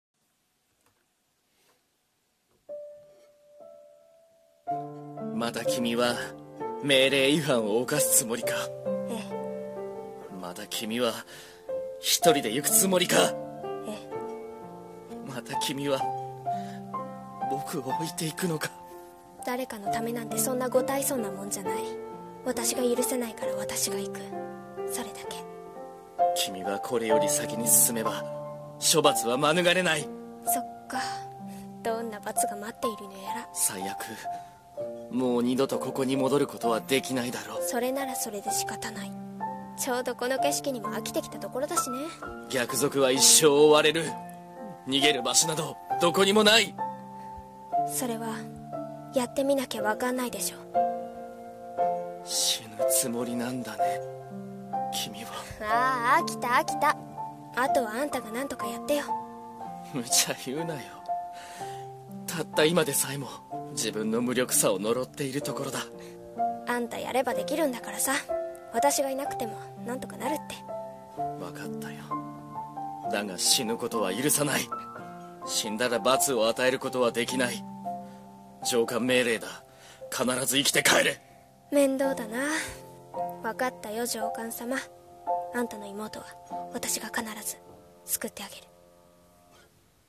声劇台本 「生きて帰れ」